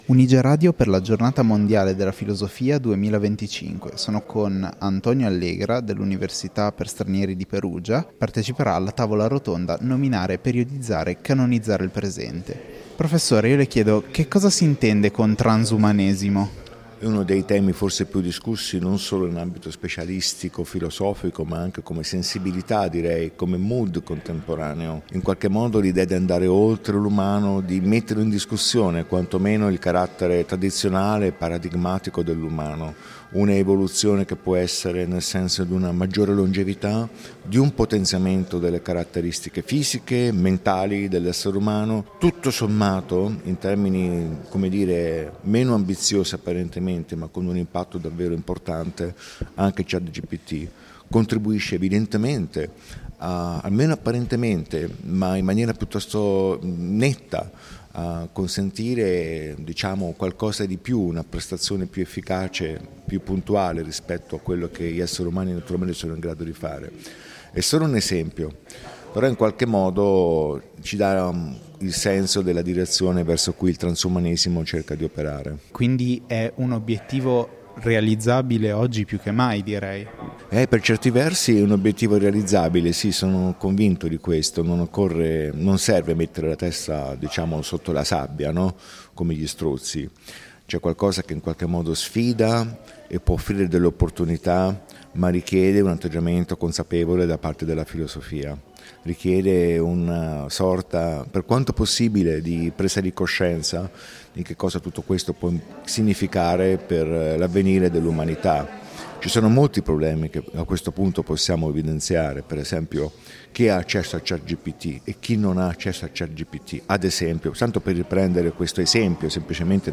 Un’intervista sul ruolo della filosofia nel valutare rischi e opportunità di un futuro sempre più tecnologico.